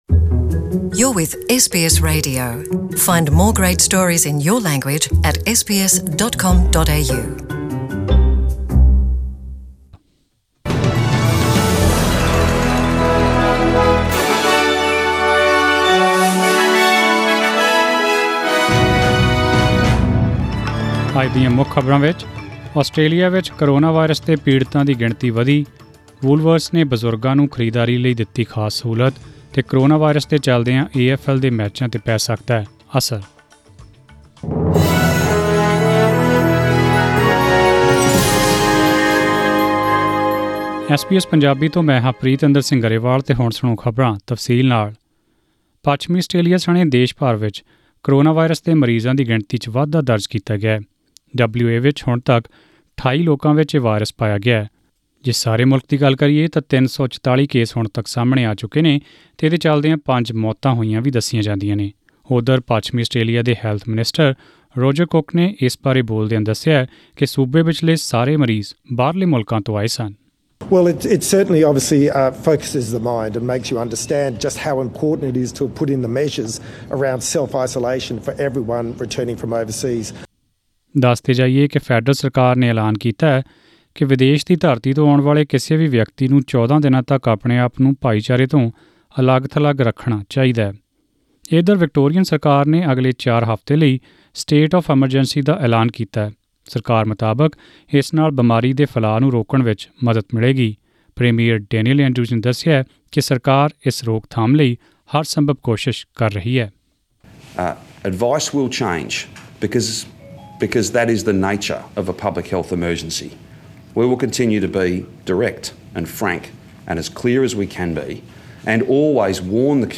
In this bulletin -